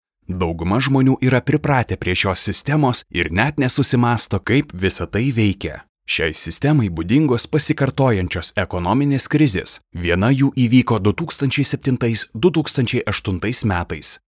Voice Over Artistes- Lithuanian